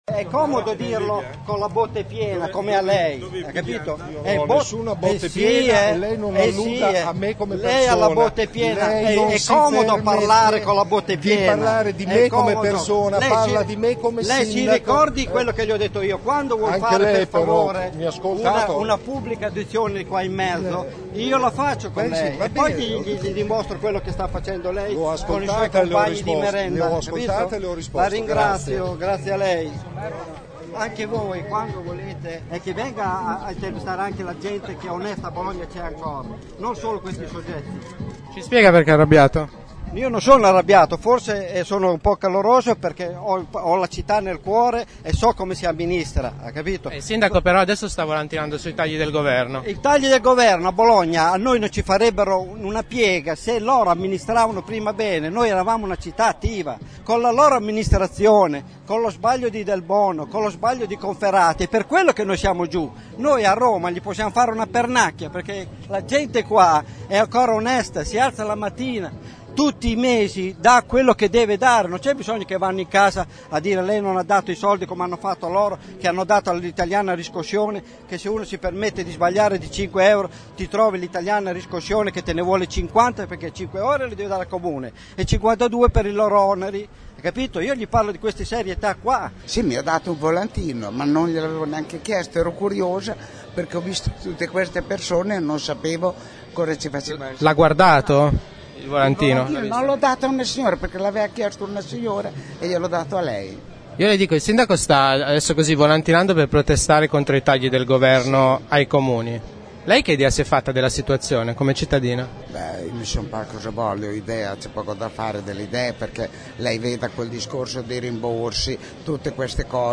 Il sindaco Merola ha volantinato per una ventina di minuti di fronte all’Urp del Comune in Piazza Maggiore.
Ascolta il battibecco tra il sindaco e un passante e i commenti della gente